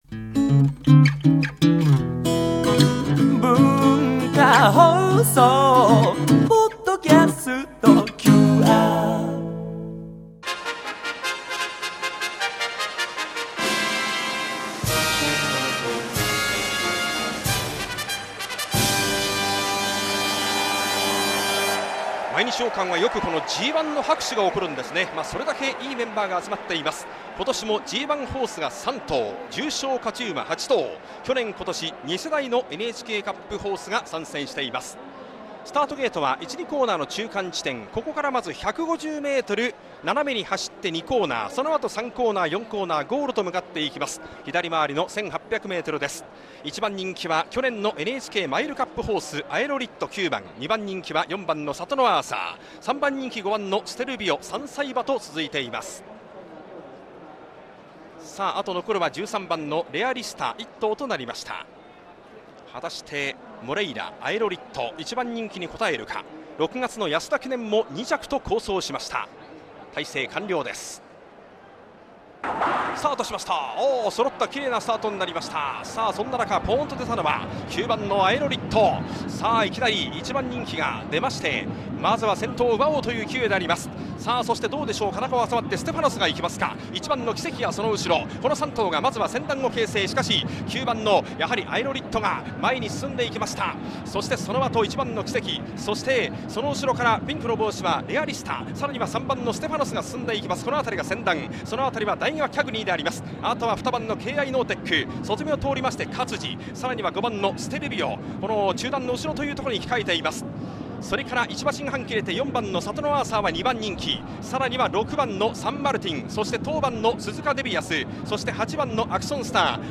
「第69回 毎日王冠（GⅡ）」の実況音をお聞きいただけます。